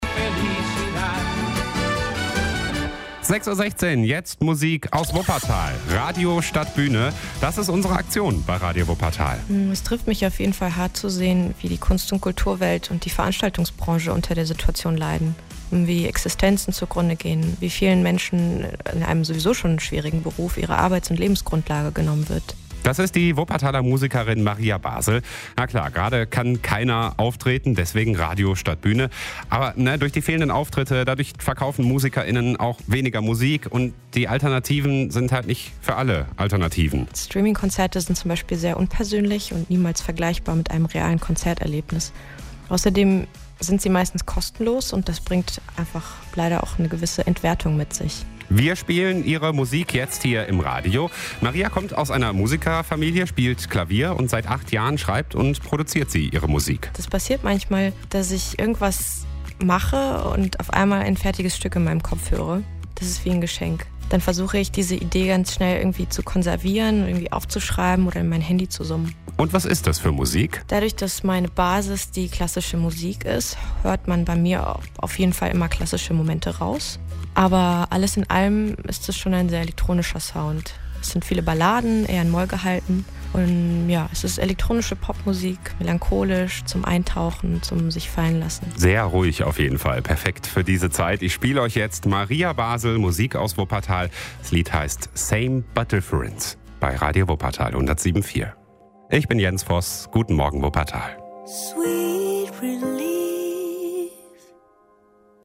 Elektronische Pop Musik/Indie.